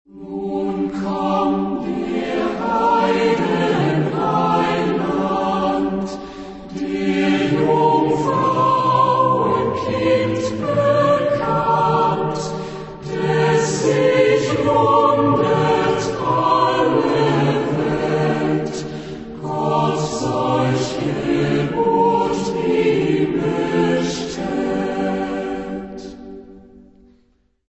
Genre-Style-Forme : Chanson ; Sacré
Type de choeur : SATB  (4 voix mixtes )
Tonalité : mode de ré